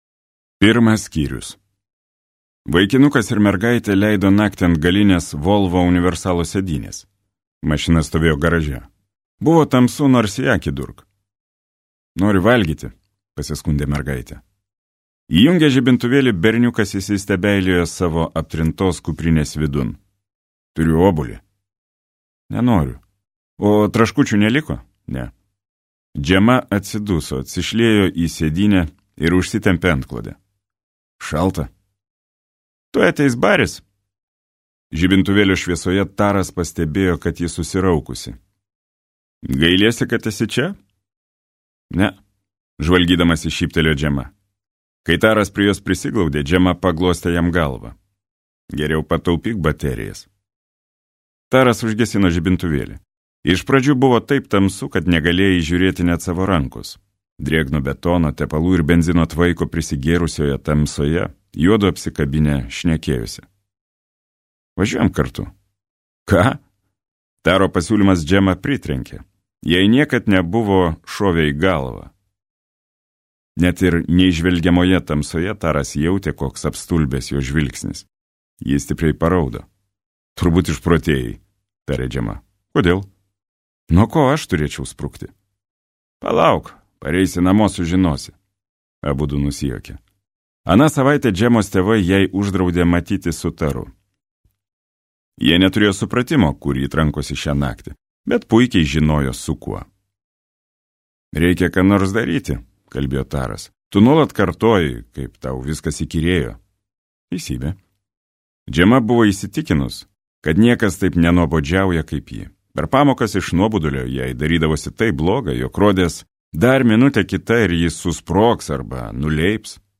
Audioknyga